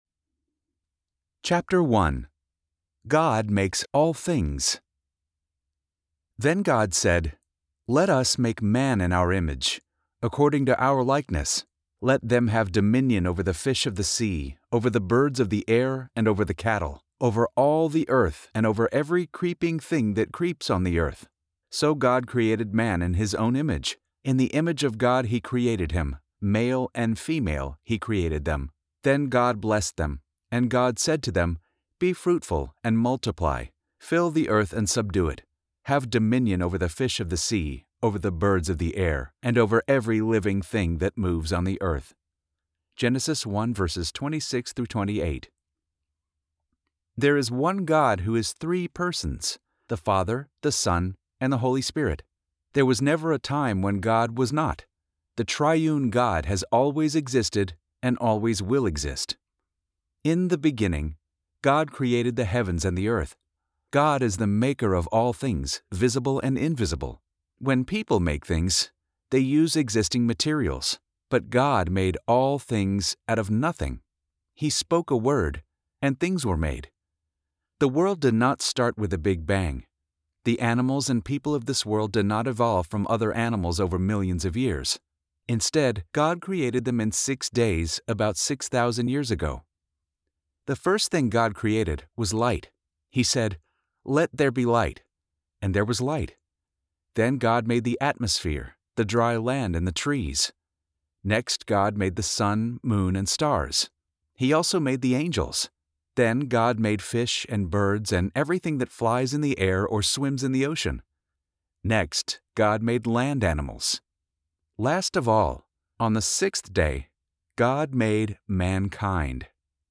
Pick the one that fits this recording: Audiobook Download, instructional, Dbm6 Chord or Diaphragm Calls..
Audiobook Download